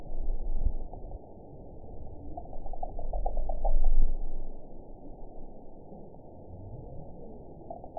event 922007 date 12/25/24 time 03:11:43 GMT (4 months, 1 week ago) score 7.66 location TSS-AB03 detected by nrw target species NRW annotations +NRW Spectrogram: Frequency (kHz) vs. Time (s) audio not available .wav